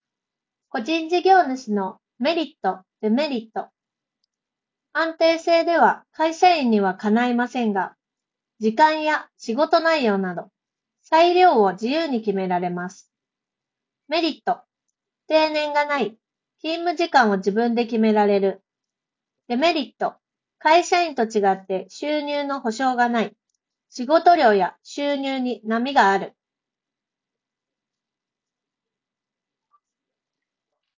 さすがに専用のハイエンドマイクと比べると録音品質は劣るものの、普通に通話するには十分に優れた性能となっている。
▼1MORE HQ31の内蔵マイクで収録した音声
周囲の環境ノイズ(空調音や屋外の音)を効果的に除去し、発言内容を明瞭に拾い上げることができていることが分かる。